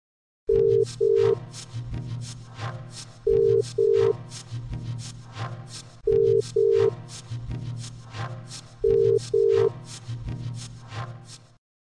这是电话接通后的嘟嘟声铃音手机铃声。